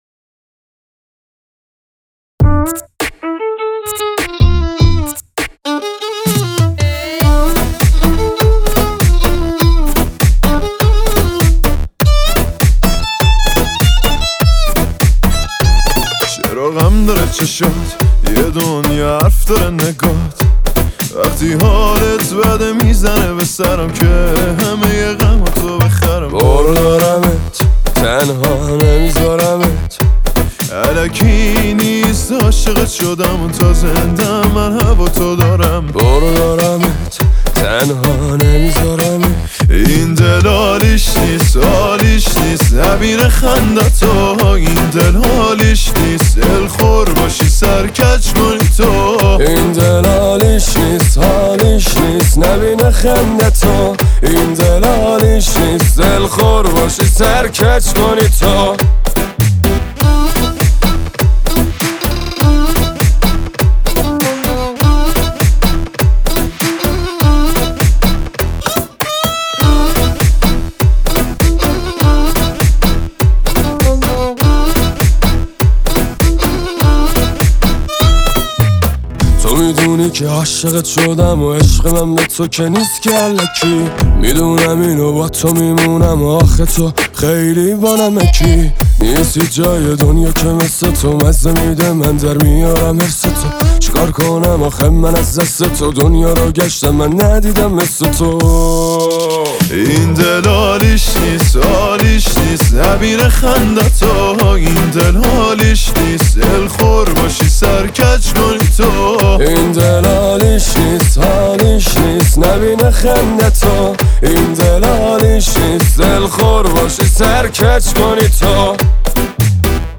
پاپ شاد عاشقانه